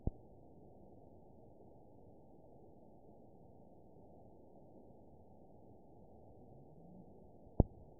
event 922872 date 04/29/25 time 09:59:44 GMT (1 month, 2 weeks ago) score 7.59 location TSS-AB01 detected by nrw target species NRW annotations +NRW Spectrogram: Frequency (kHz) vs. Time (s) audio not available .wav